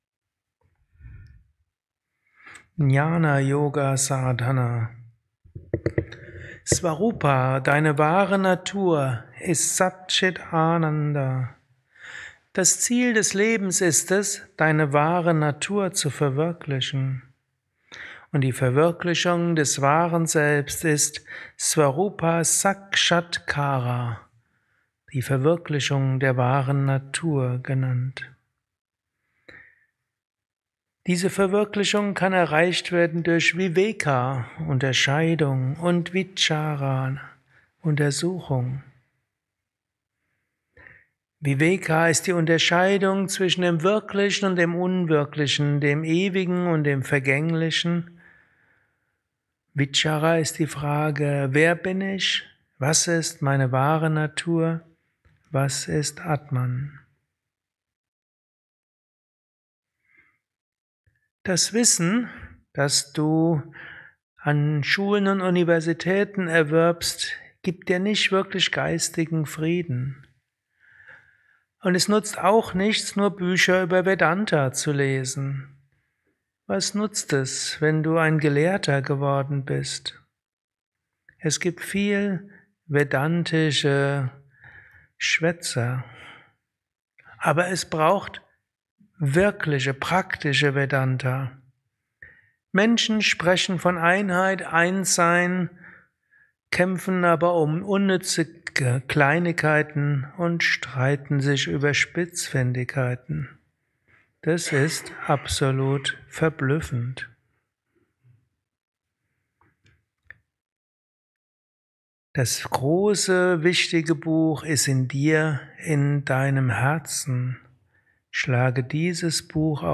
kurzer Vortrag als Inspiration für den heutigen Tag von und mit
eines Satsangs gehalten nach einer Meditation im Yoga Vidya